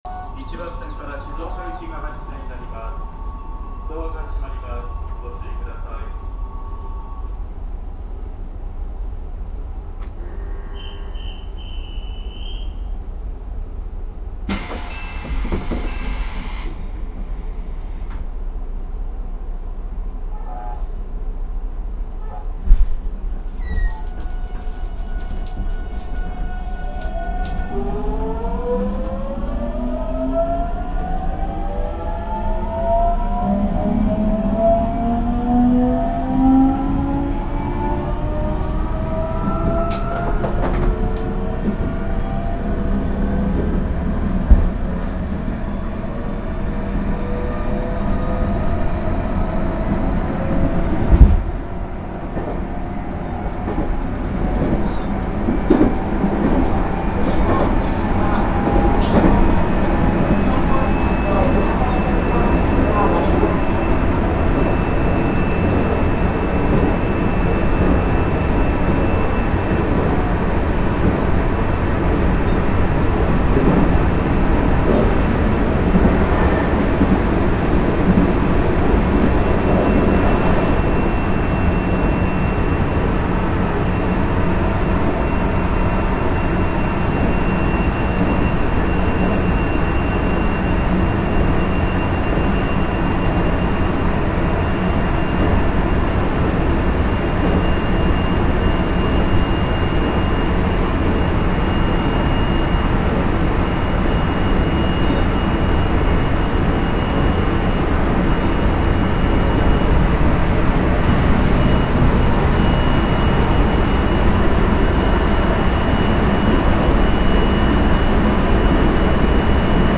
制御装置は383系で採用された東芝製の個別制御で、207系1000番代や223系0番代に似た変調音がします。減速時では回生制動の他に、発電制動も使用されています。
走行音（F6・クモハ373-6 / 発電・回生併用）　132Kbps
収録区間：東海道本線 函南→三島